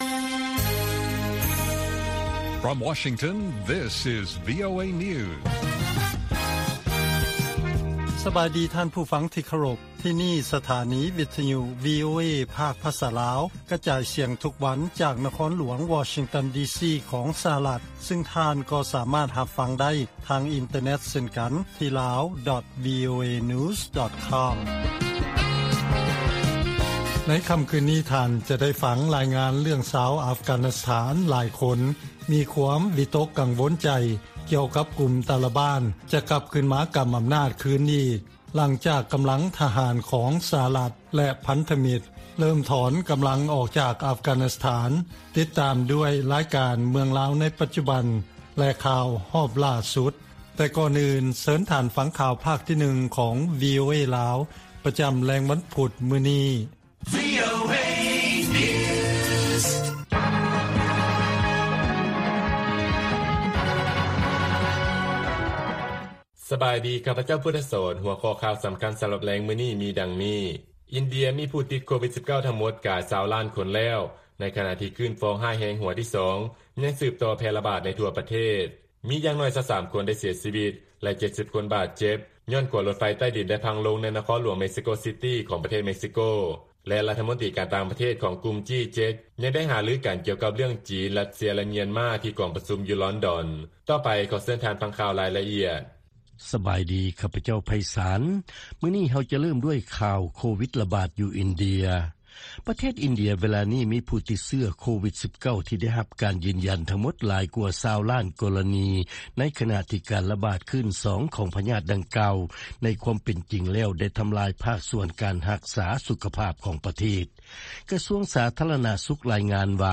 ວີໂອເອພາກພາສາລາວ ກະຈາຍສຽງທຸກໆວັນ. ຫົວຂໍ້ຂ່າວສໍາຄັນໃນມື້ນີ້ມີ: 1) ອິນເດຍມີຜູ້ຕິດເຊື້ອ ພະຍາດໂຄວິດ-19 ທີ່ໄດ້ຮັບການຢືນຢັນຫຼາຍກວ່າ 20 ລ້ານຄົນແລ້ວ. 2) ຊາວອັຟການິສຖານ ຢ້ານວ່າ ກຸ່ມຕາລີບານ ຈະກັບມາກຳອຳນາດຄືນອີກ ຫລັງຈາກ ສຫລ ຖອນທະຫານອອກໄປ ແລະຂ່າວສໍາຄັນອື່ນໆອີກ.